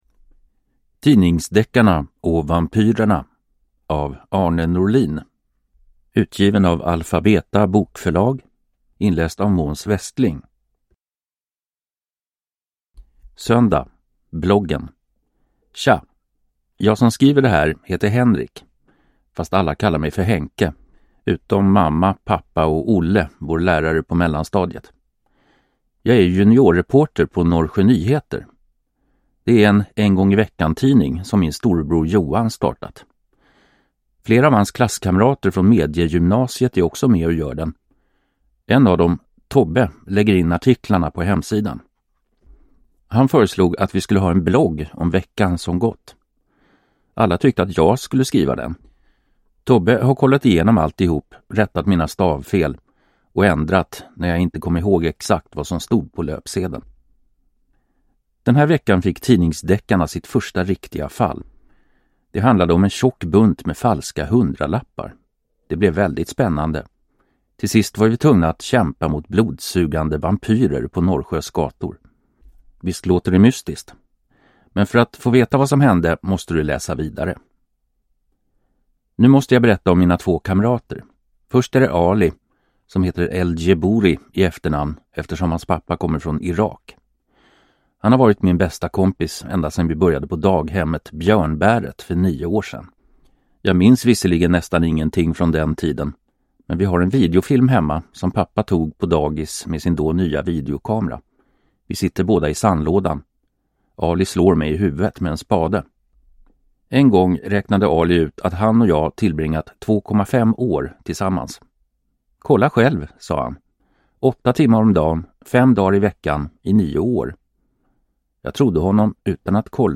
Tidningsdeckarna och vampyrerna – Ljudbok